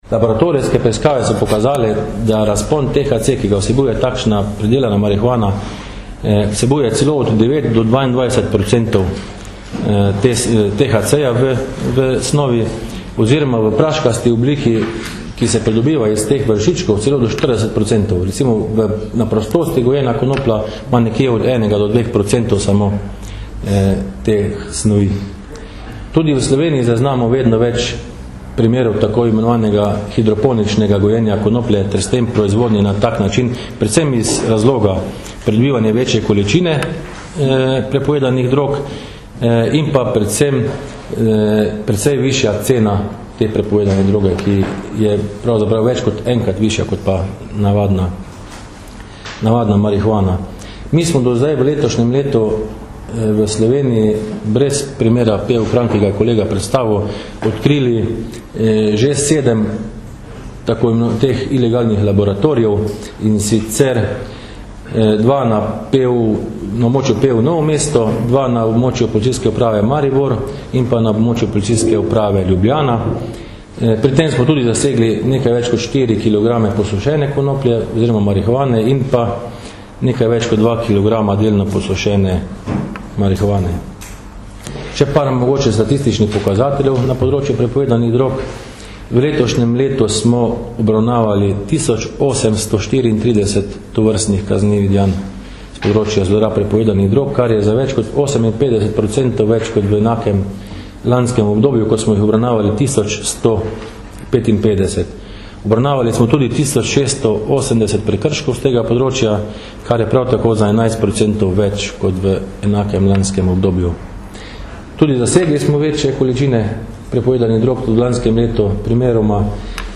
Policija - Razkrili kriminalno združbo preprodajalcev marihuane - informacija z novinarske konference